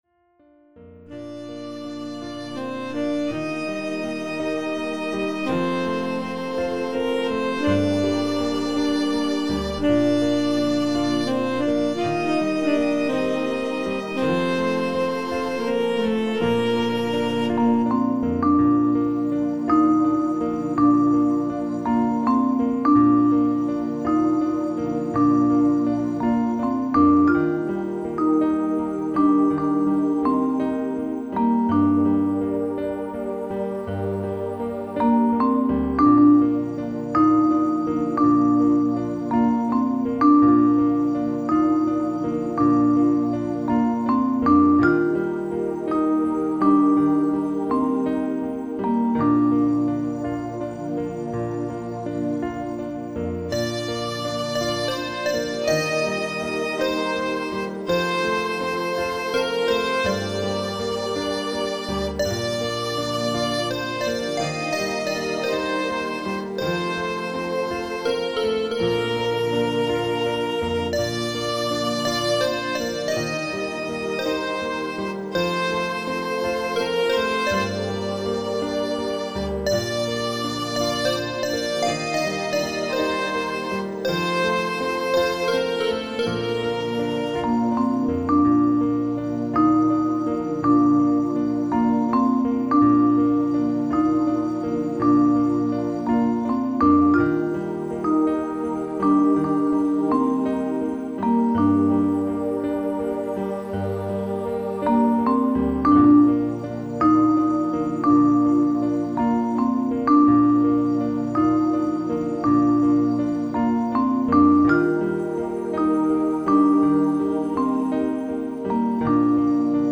Новая пропитывающая музыка